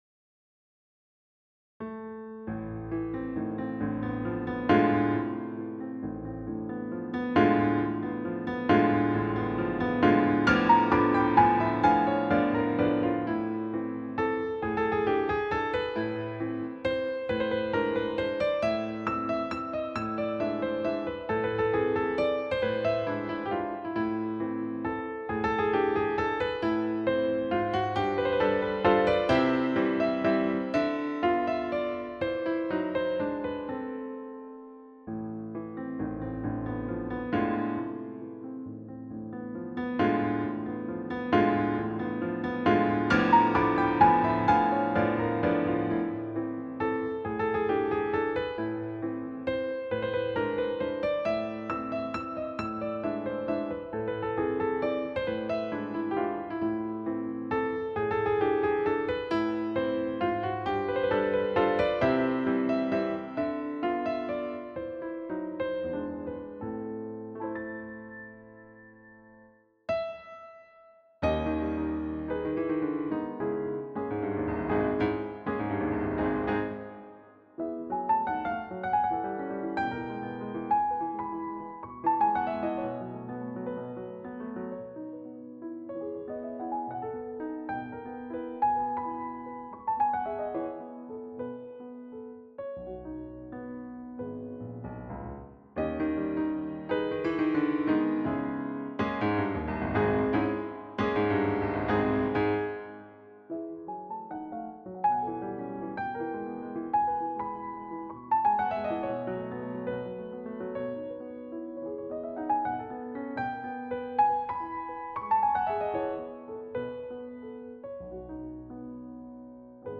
2024 Waltz Completed Click to listen.